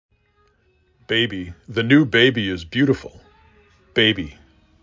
bA b E
Local Voices
Ireland